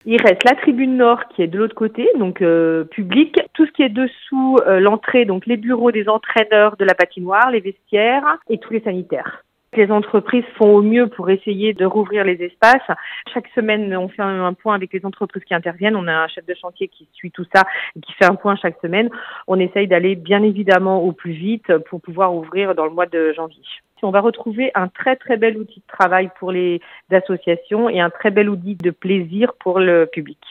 Le point avec Catherine Allard, adjointe à la mairie d’Annecy, en charge des sports et des associations sportives.